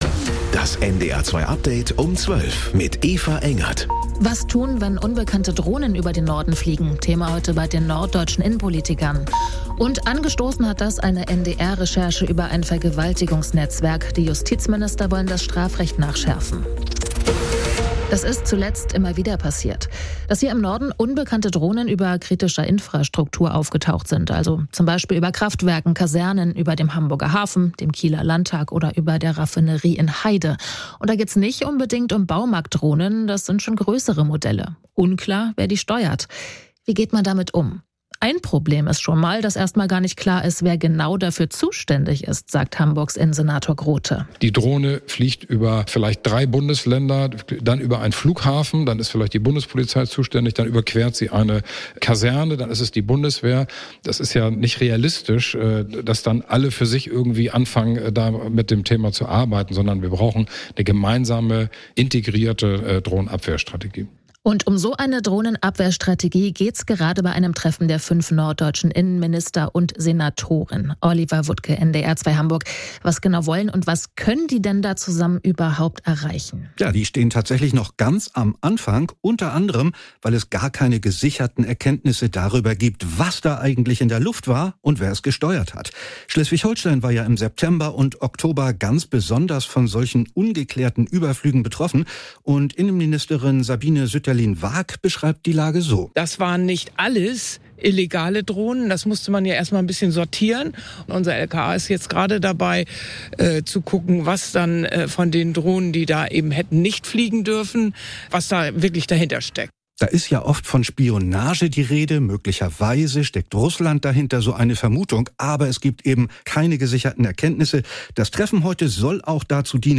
Mit unseren Korrespondent*innen und Reporter*innen, im Norden, in Deutschland und in der Welt.